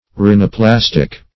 Search Result for " rhinoplastic" : The Collaborative International Dictionary of English v.0.48: Rhinoplastic \Rhi`no*plas"tic\, a. [Rhino- + -plastic: cf. F. rhinoplastique.]